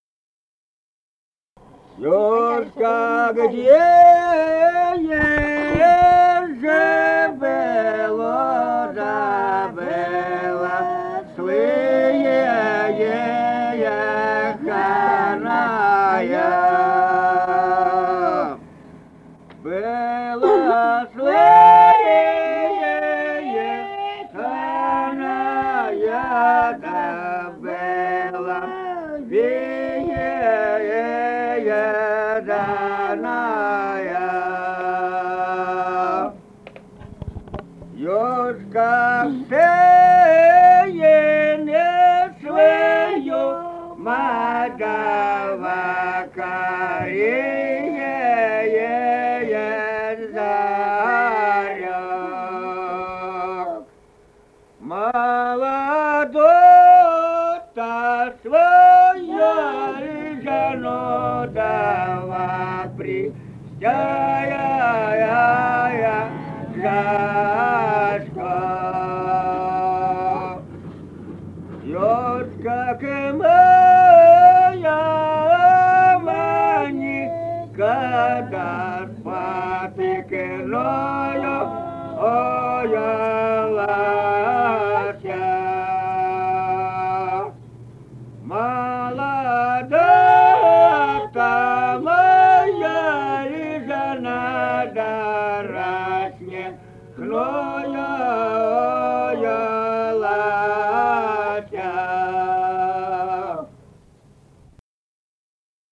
Республика Казахстан, Восточно-Казахстанская обл., Катон-Карагайский р-н, с. Коробиха, июль 1978.